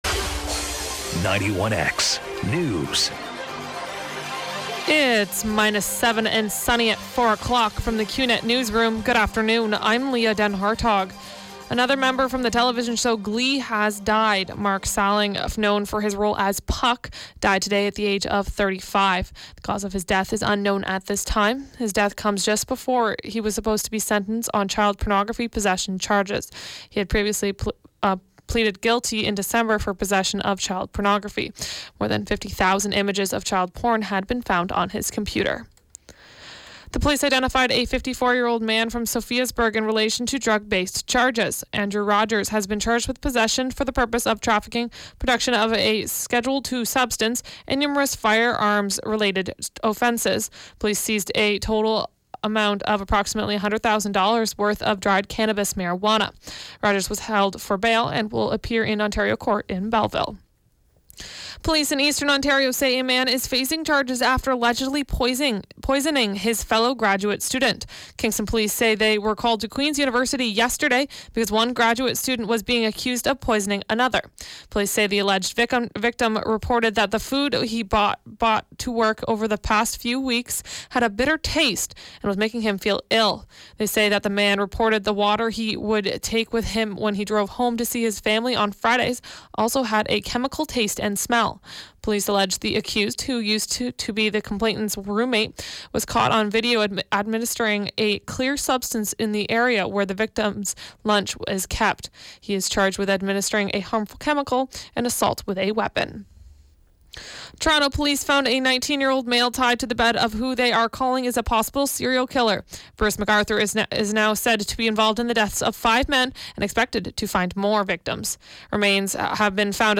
91X Newscast: Tuesday, Jan. 30, 2018, 4 p.m.